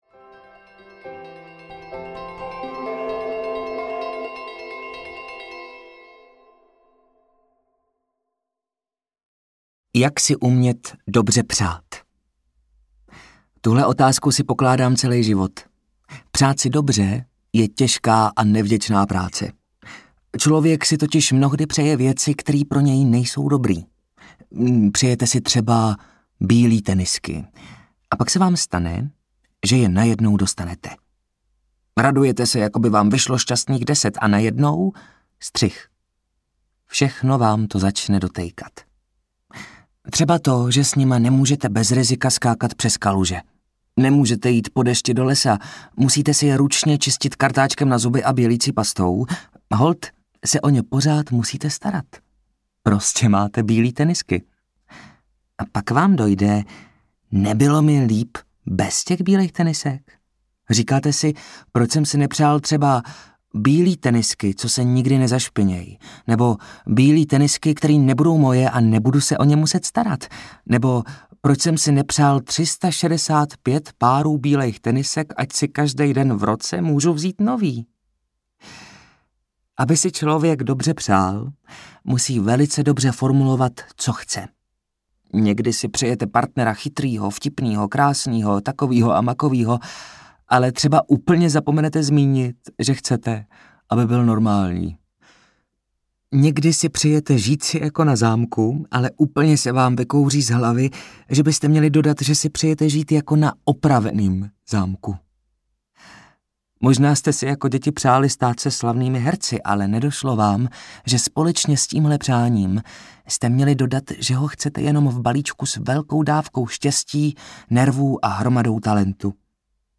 Nadělení audiokniha
Ukázka z knihy